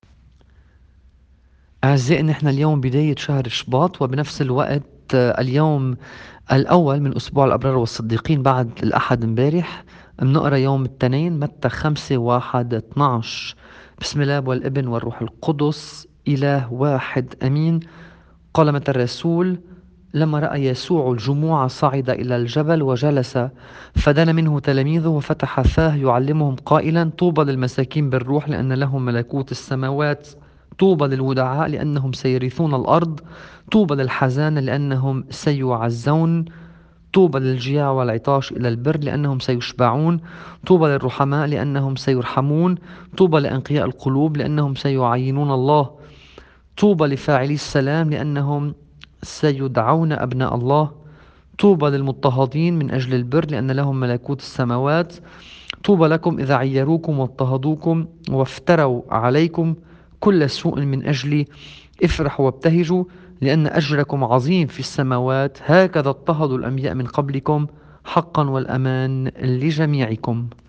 إنجيل القدّيس متّى .12-1:5